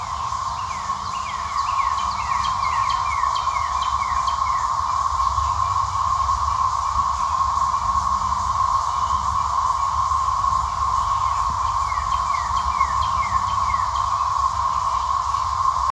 Cicada
Below is a picture of a cicada in my yard in May 2024.
You can hear what a group of cicadas sould like by clicking
Cicada_May_2024.wav